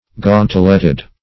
Gauntletted \Gaunt"lett*ed\